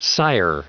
Prononciation du mot sigher en anglais (fichier audio)
Prononciation du mot : sigher